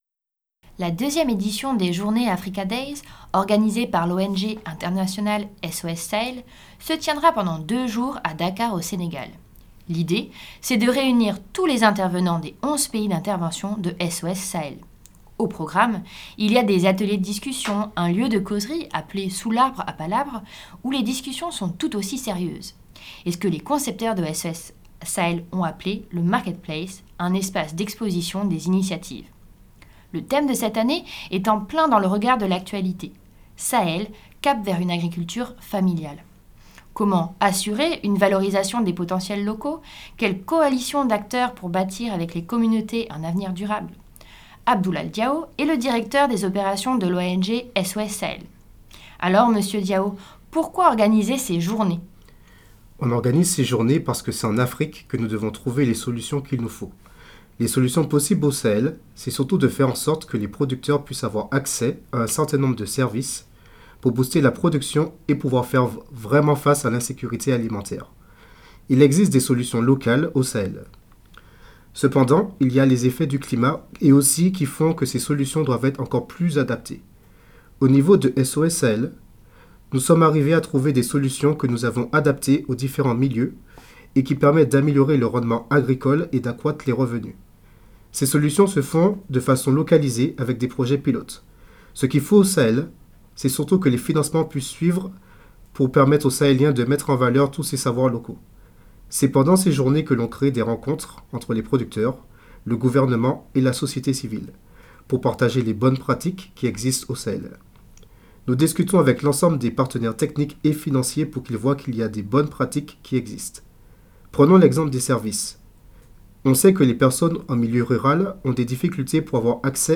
En écoutant ces interventions lors d’événements en Europe et au Sénégal sur le développement durable, vos apprenant(e)s pourront acquérir les compétences pour rédiger un compte-rendu.